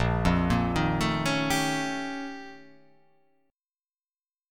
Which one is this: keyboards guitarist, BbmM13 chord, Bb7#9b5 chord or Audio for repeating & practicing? BbmM13 chord